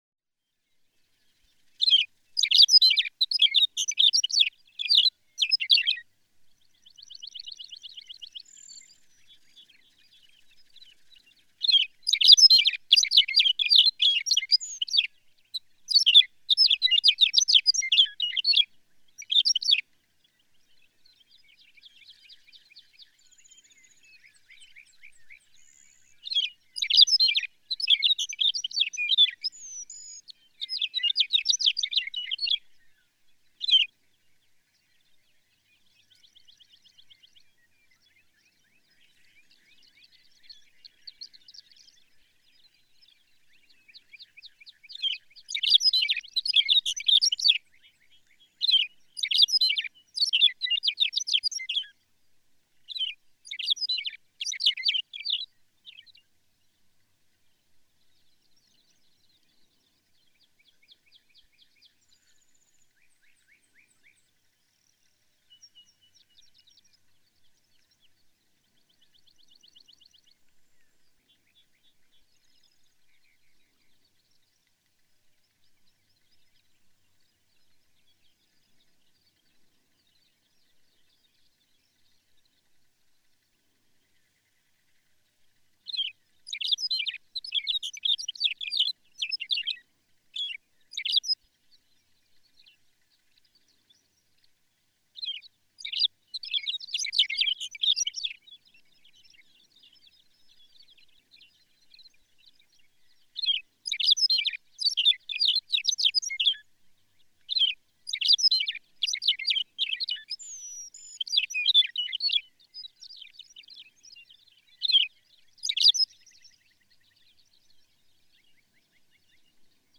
McCown’s longspur
A tinkling, tumbling array of song notes as he parachutes back to earth.
Murphy's Pasture, Pawnee National Grassland, Colorado.
548_McCown's_Longspur.mp3